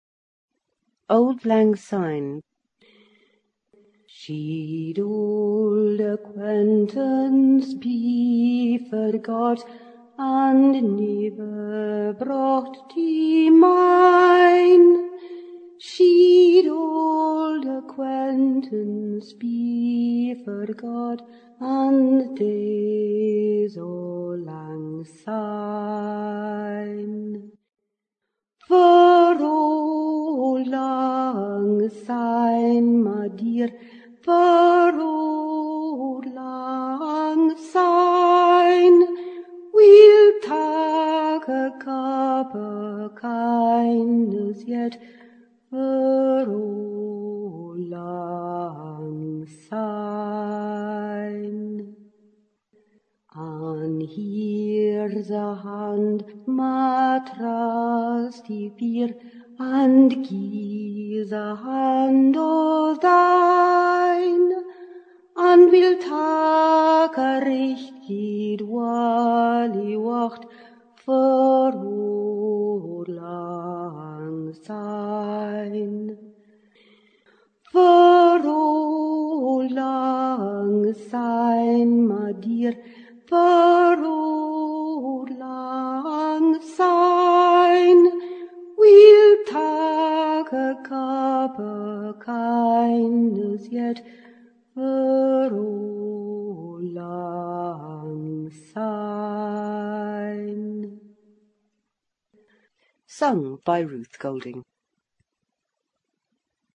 Cette version abrégée typiquement chantée le soir de la Saint Sylvestre provient d'une chanson bien plus longue.